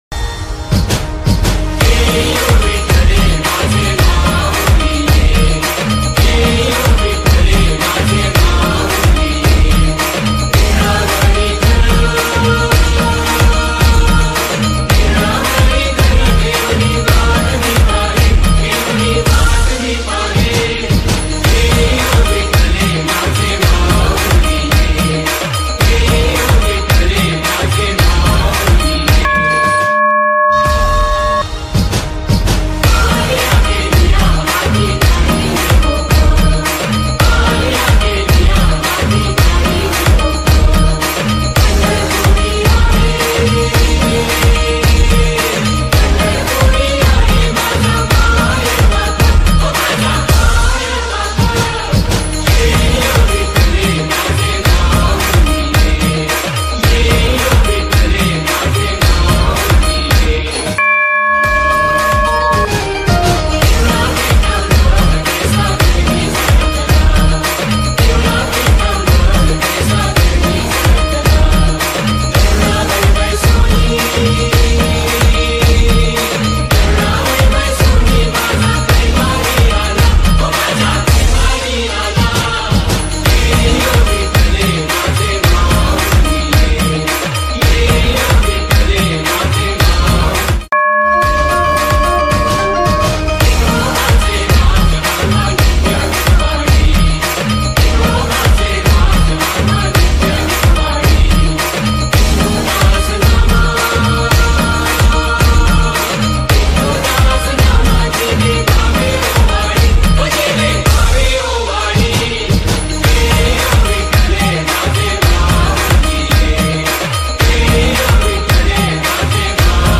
Marathi devotional song